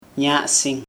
[ɲaʔsiŋ] noun summertime
Dialect: Hill Remo